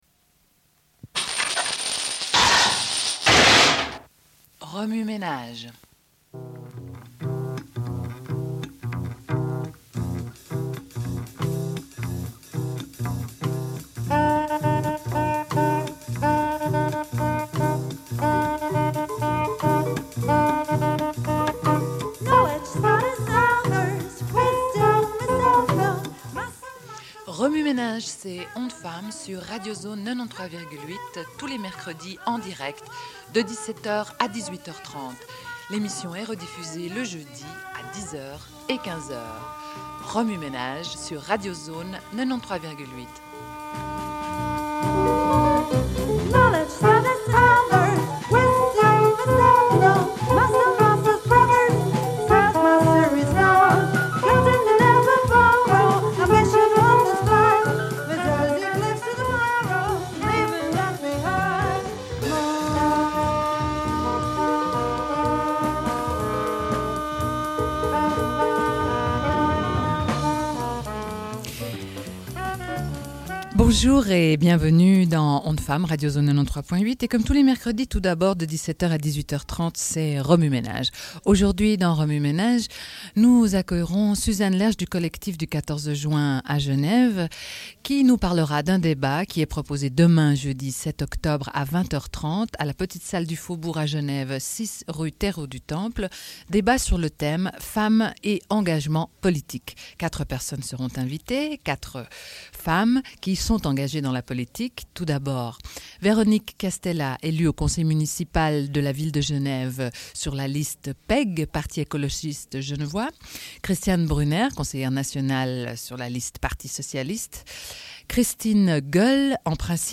Une cassette audio, face A31:23
Radio Enregistrement sonore